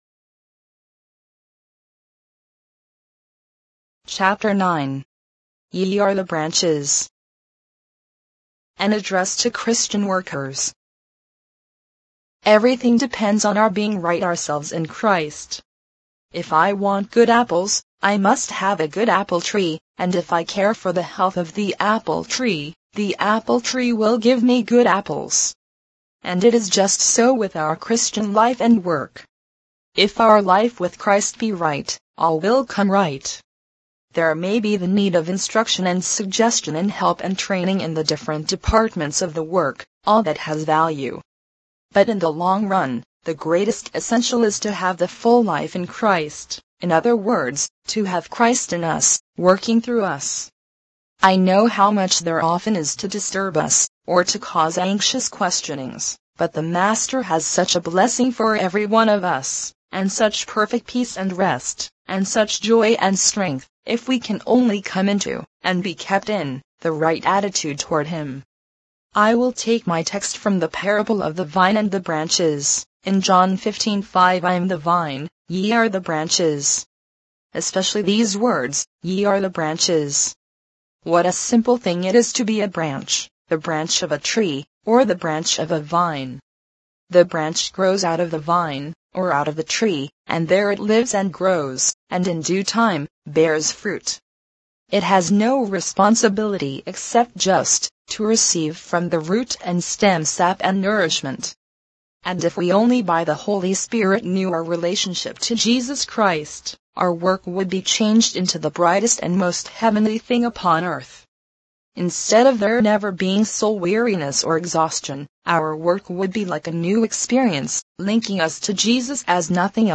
Free Christian audiobooks. Absolute Surrender by Andrew Murray in mp3 audio format.
Free Christian Audio Books Digital Narration for the 21st Century